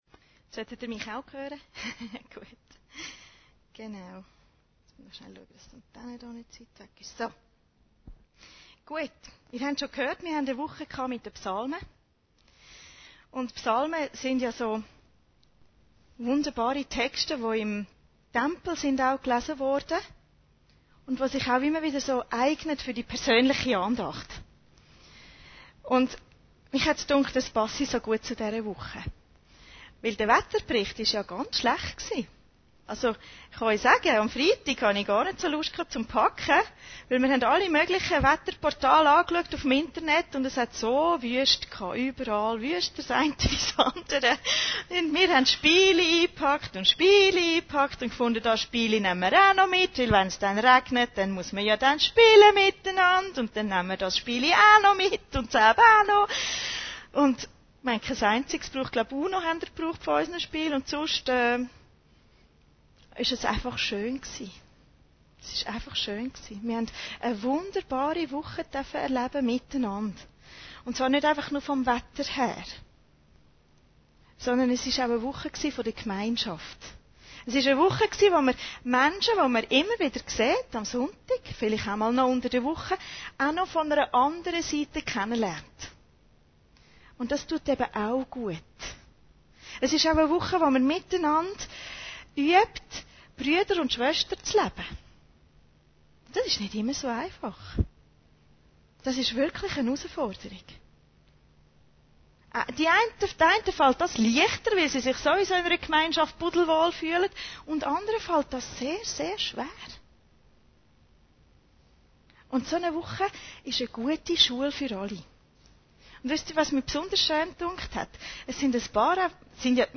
Predigten Heilsarmee Aargau Süd – Psalm 133